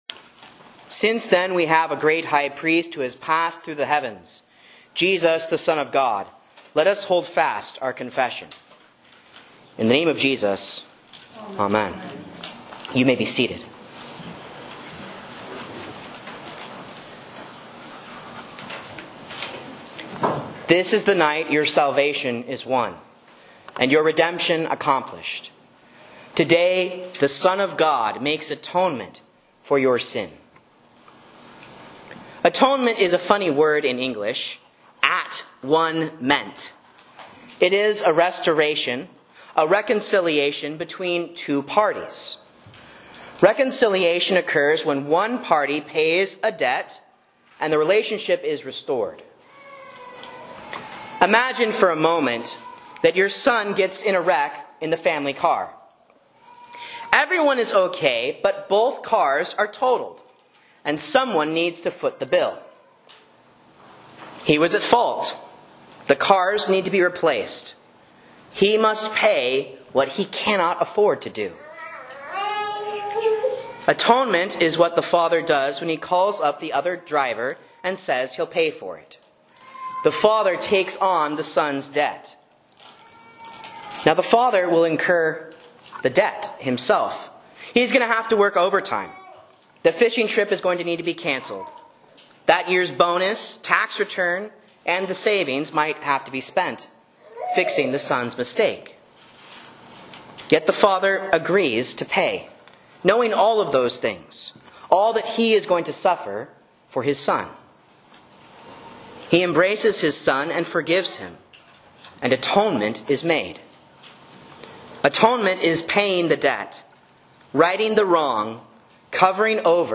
A Sermon on Hebrews 4:14 & St. John's Passion for Good Friday